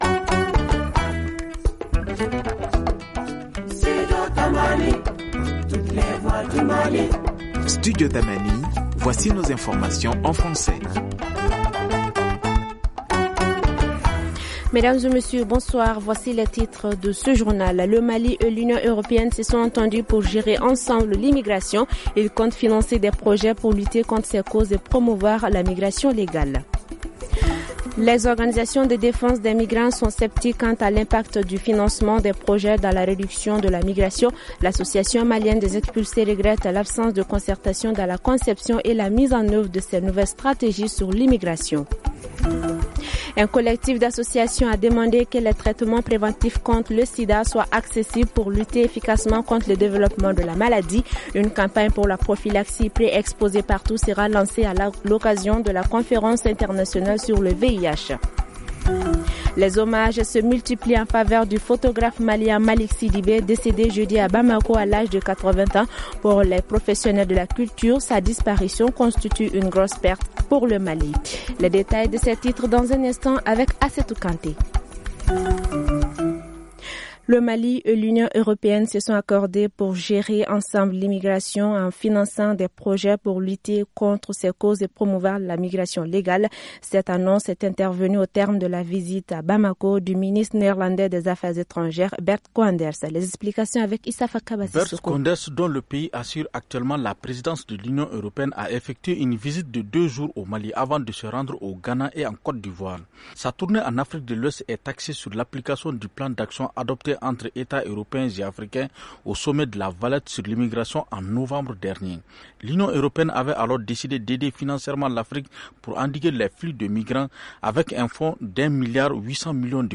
Ci-dessous, écoutez le développement de ces titres dans nos journaux en français :